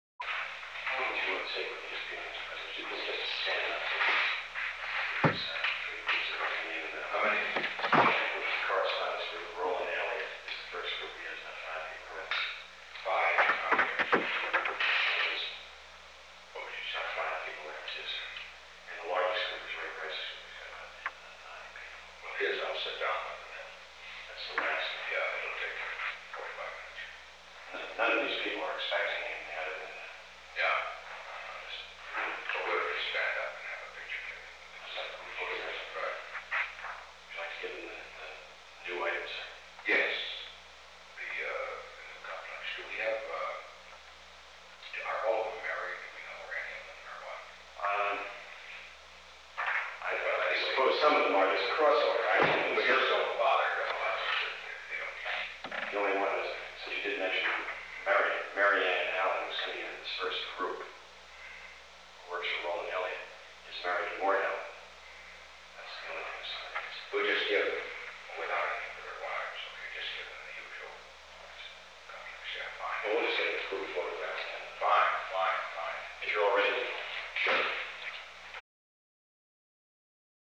The Oval Office taping system captured this recording, which is known as Conversation 751-010 of the White House Tapes.
Location: Oval Office